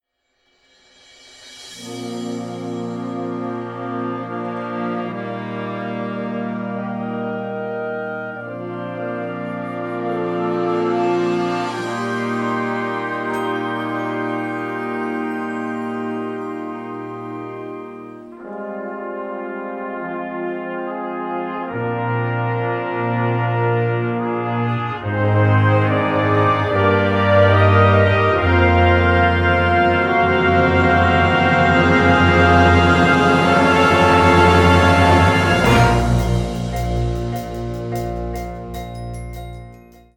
Kategorie Blasorchester/HaFaBra
Unterkategorie Ouvertüre (Originalkomposition)
Besetzung Ha (Blasorchester)